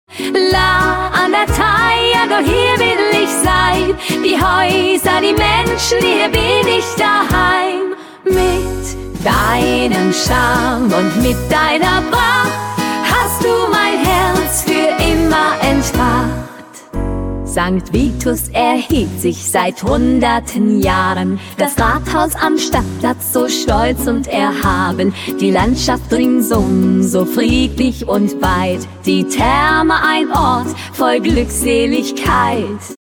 Traditionelle, Volkstümliche Musik!